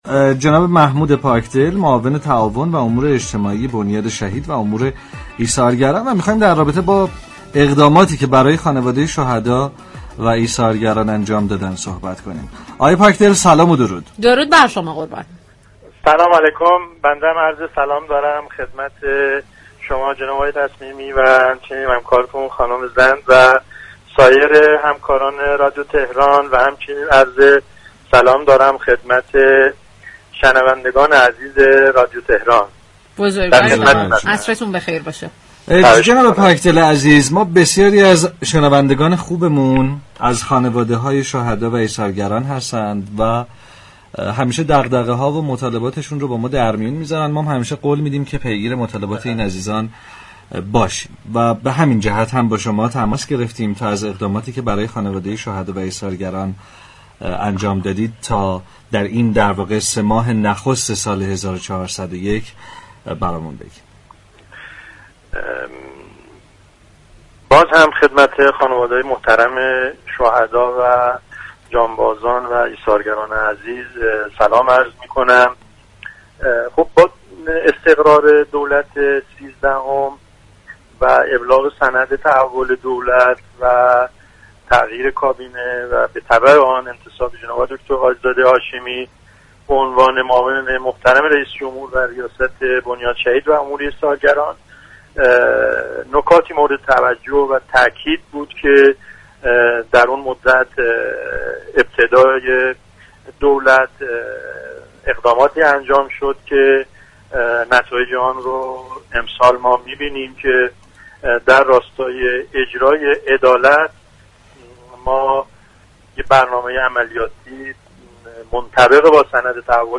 به گزارش پایگاه اطلاع رسانی رادیو تهران، محمود پاكدل معاون تعاون و امور اجتماعی بنیاد شهید و امور ایثارگران در گفتگو با فرحزاد رادیو تهران درمورد فعالیت‌های بنیاد شهید و امور ایثارگران در سال جاری گفت: در دوره جدید و با ابلاغ برنامه تحول دولت مردمی نكاتی مورد توجه و تأكید بود و از ابتدای دولت اقداماتی انجام شد كه نتایج آن را در سال جاری مشاهده می‌كنیم.